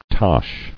[tosh]